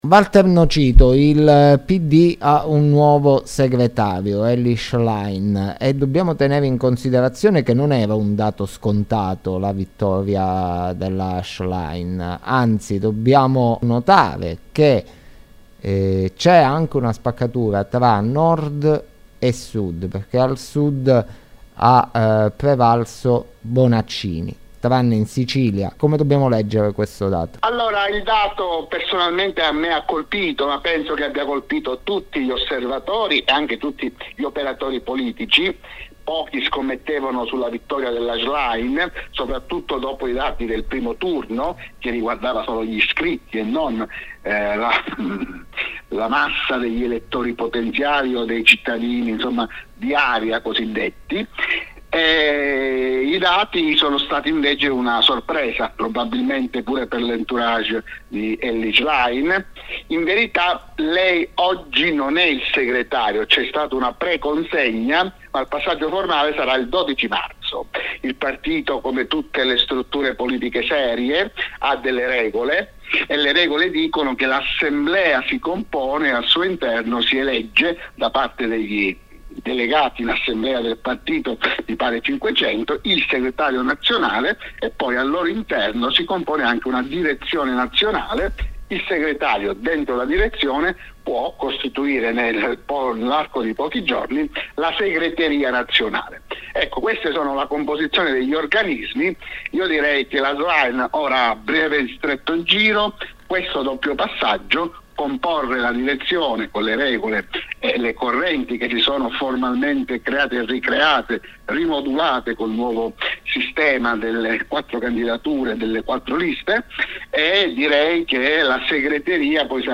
Interviste & Podcast